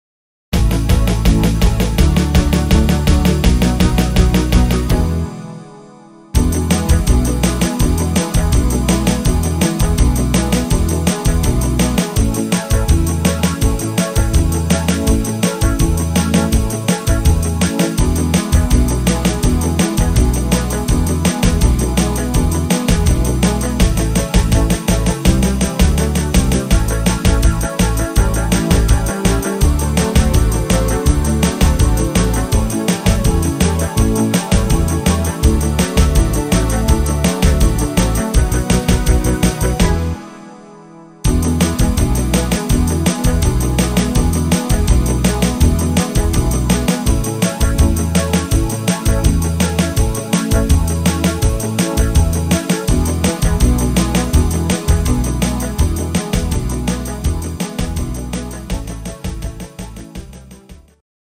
(instr. Gitarre)